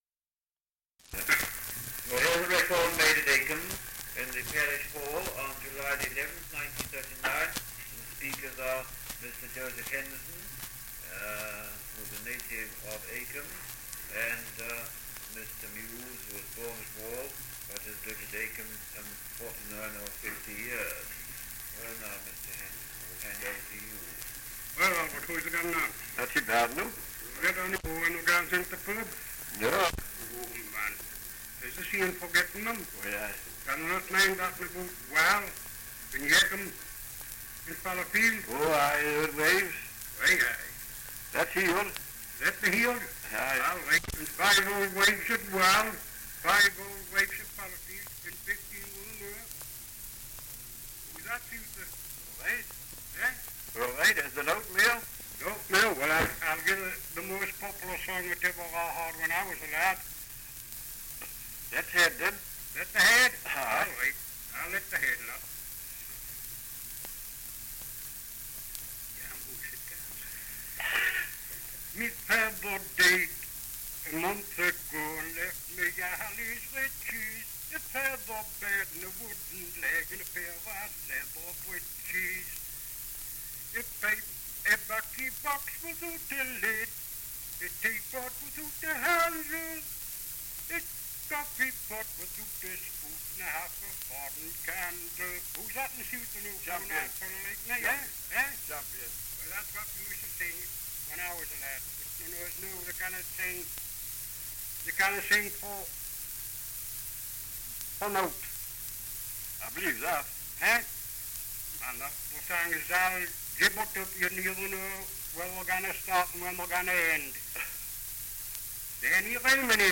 2 - Dialect recording in Acomb, Northumberland
78 r.p.m., cellulose nitrate on aluminium
English Language - Dialects